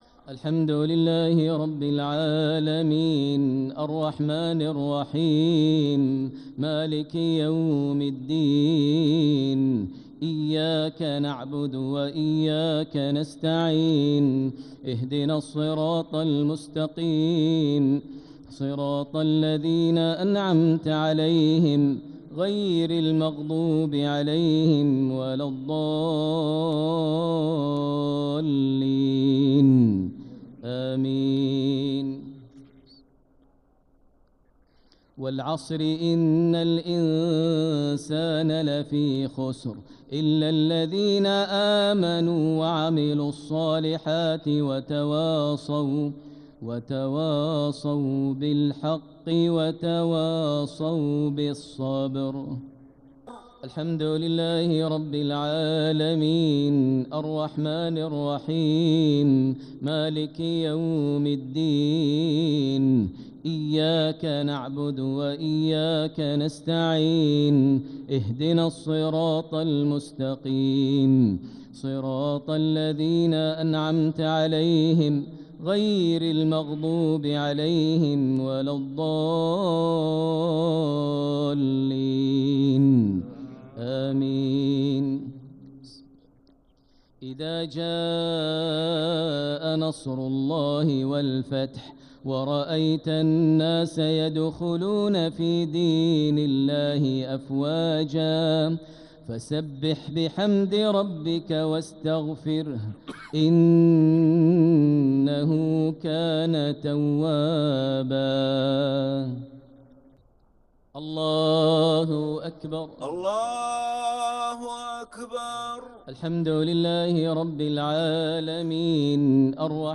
الشفع والوتر ليلة 24 رمضان 1446هـ > تراويح 1446 هـ > التراويح - تلاوات ماهر المعيقلي